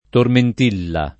[ torment & lla ]